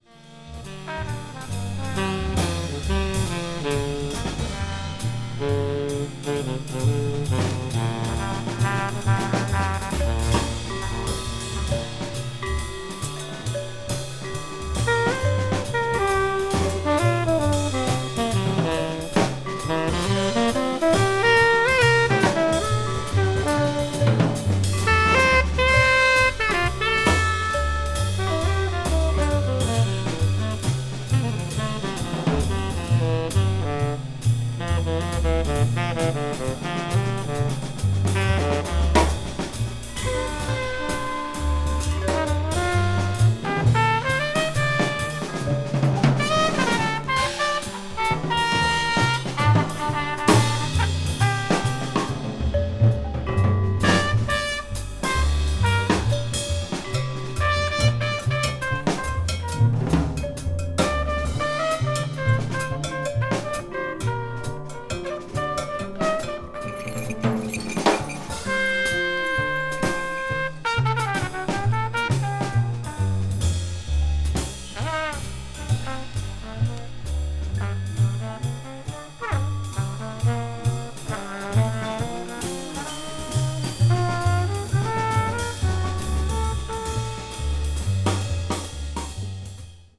同年に本拠地シカゴのスタジオで録音されています。